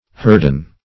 Search Result for " hurden" : The Collaborative International Dictionary of English v.0.48: Hurden \Hur"den\, n. [From Hurds .] A coarse kind of linen; -- called also harden .